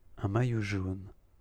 pronounce each phrase.